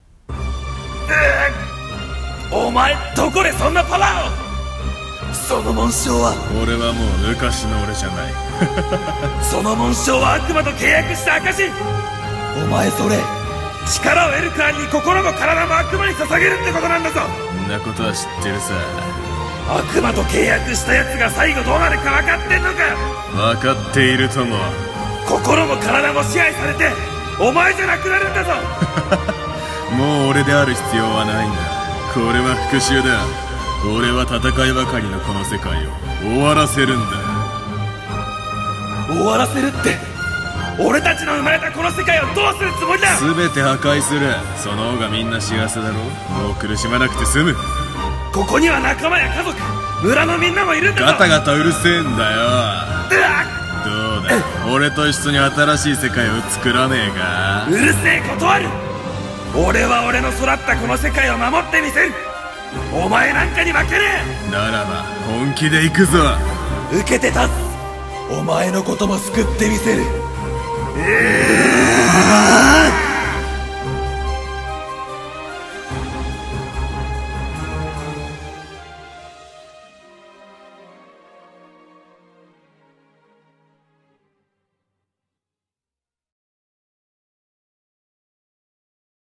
声劇 戦い系 2人用